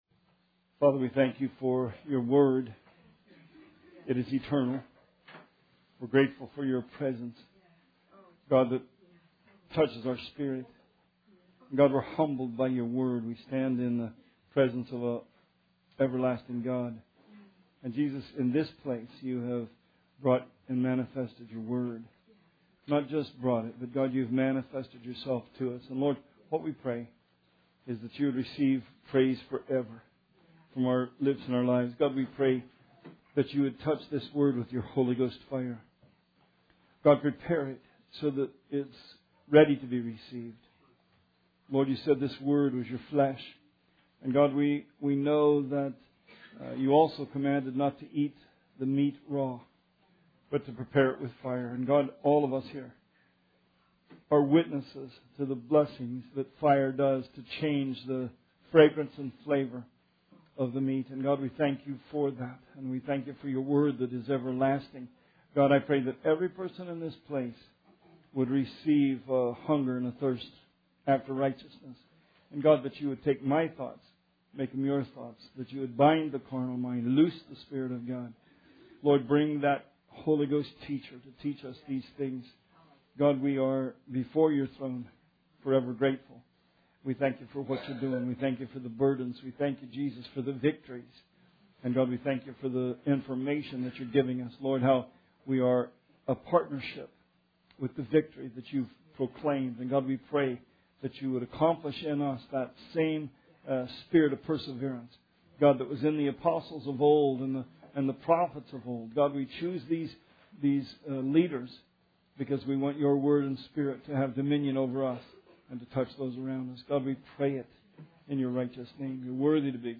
Sermon 12/11/16